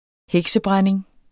Udtale [ ˈhεgsəˌbʁaneŋ ]